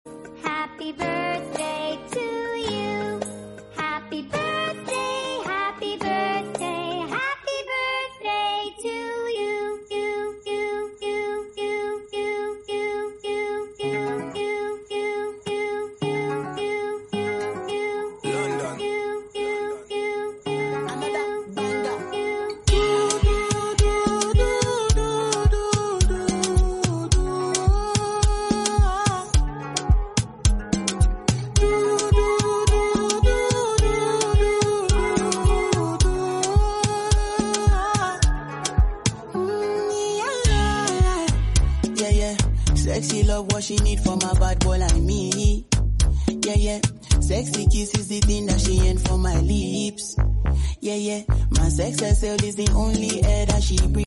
lullabies